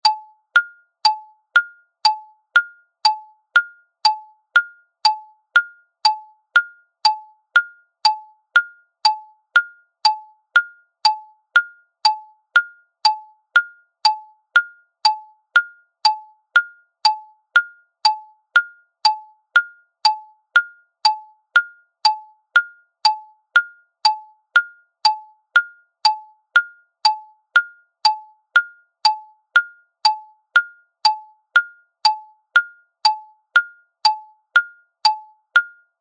シンプルながら明るく響くマリンバの音色が、謎解きや問題解決の時間を演出し、集中力を高めるお手伝いをします。